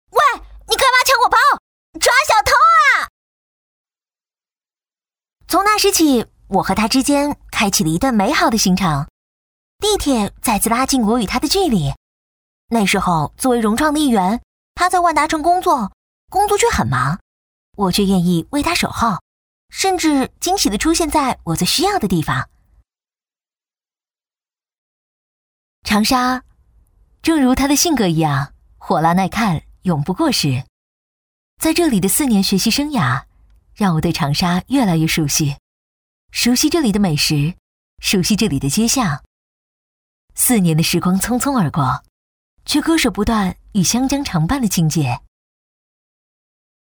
女29-角色扮演【融创我与他】
女29-大气磁性 轻松活泼